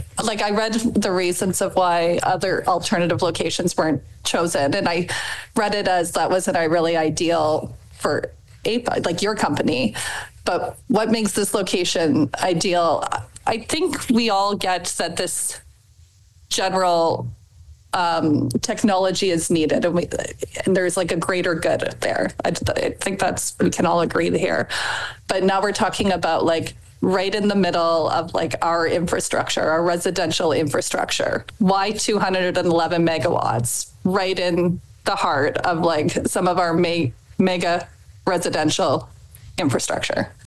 Council also took turns asking questions with many focusing on the location. Councillor Jennifer Adams had concerns regarding the size of the facility and the proximity to a future residential site.